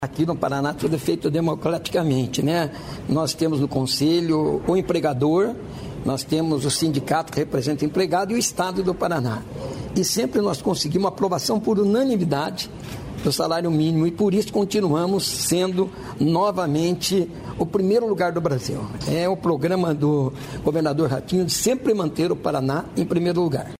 Sonora do secretário do Trabalho, Mauro Moraes, sobre o novo piso regional